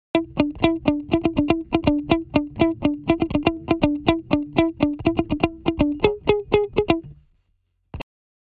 前回、録音で取り入れたギターは大きなリズムが中心だったため、今回は細かいリズムのフレーズを探します。
「音源：エレキギター」→「ジャンル：ファンク」→「詳細表示：クリーン、ドライ、グルーブ」で絞り込み、検索結果からイメージにぴったりだった下記のループを選択しました。
▶ギターループのサウンド
Logic11-Appleloops-Guitar.mp3